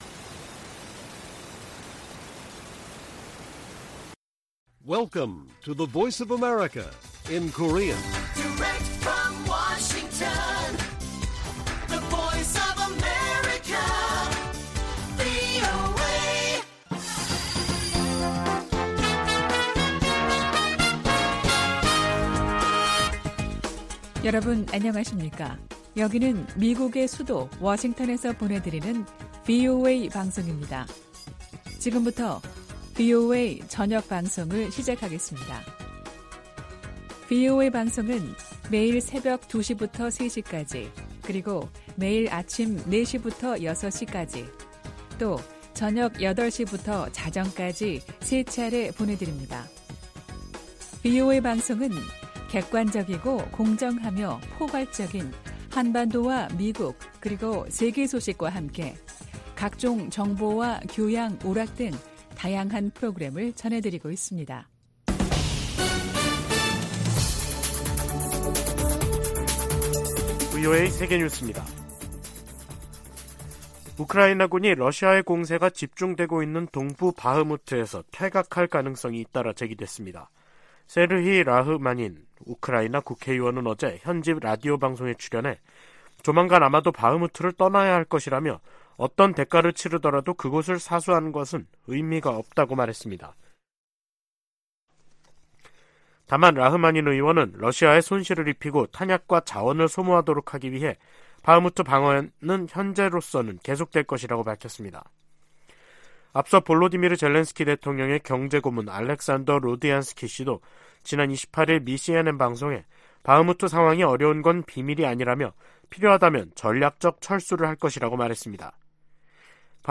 VOA 한국어 간판 뉴스 프로그램 '뉴스 투데이', 2023년 3월 2일 1부 방송입니다. 미 국무부는 윤석열 한국 대통령의 3∙1절 기념사가 한일관계의 미래지향적 비전을 제시했다며 환영의 뜻을 밝혔습니다. 최근 실시한 미한일 탄도미사일 방어훈련이 3국 협력을 증진했다고 일본 방위성이 밝혔습니다. 미 하원에 한국전쟁 종전 선언과 평화협정 체결, 미북 연락사무소 설치 등을 촉구하는 법안이 재발의됐습니다.